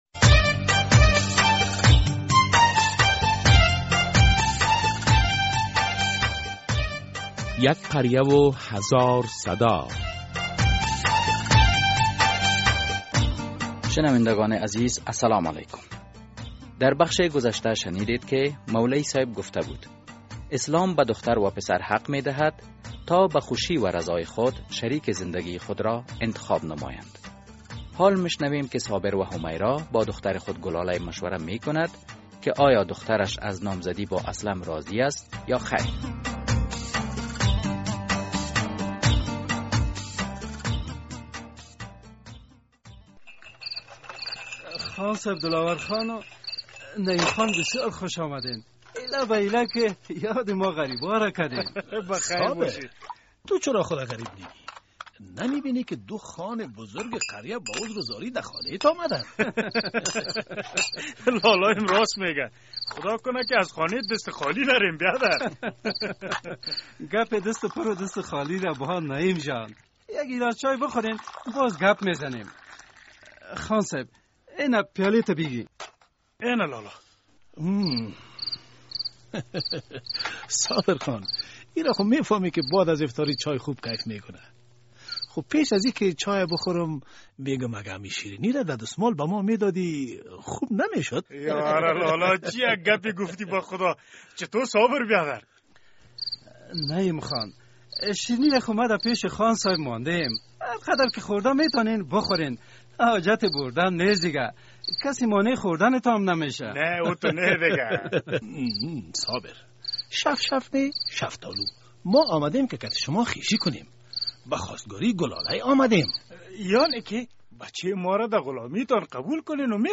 در خانه و قریه با دختران و پسران چگونه رفتار مناسب است. در قسمت ۱۹۳ درامه یک قریه هزار صدا نظریات مسافر و خانواده وی را می‌شنوید ...